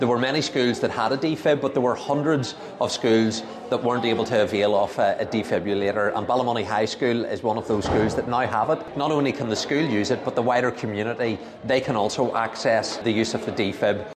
Paul Givan says it benefits more than just those in the school: